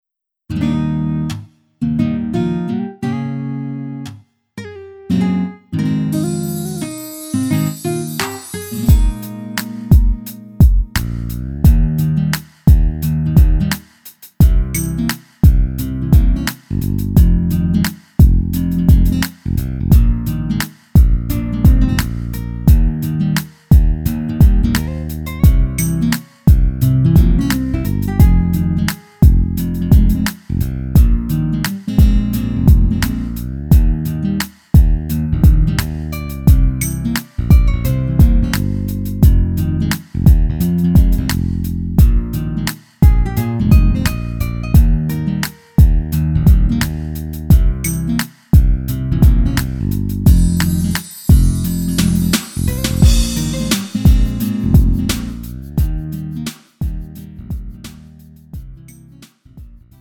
음정 -1키 3:45
장르 가요 구분 Lite MR